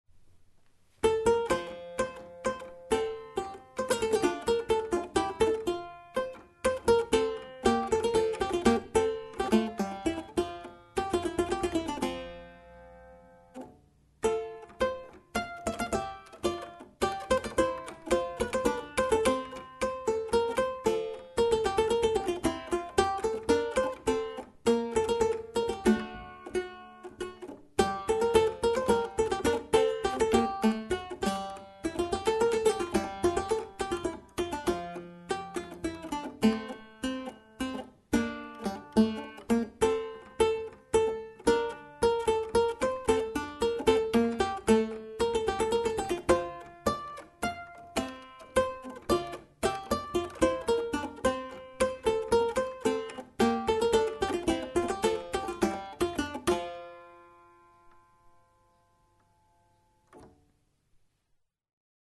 Clavicordo
CLAVICORDO-EnAvois.mp3